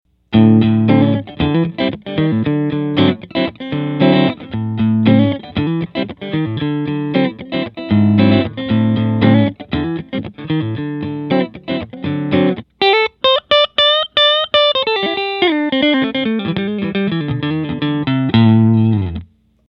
I used my loop station to record a 20 second guitar riff used it to record 5 different amp settings before I modded the amp.
For test two, I turned the bass all the way up.
Anyways, even with the bass cranked, the “before” example sounds like a little guy trying to sound like a big guy.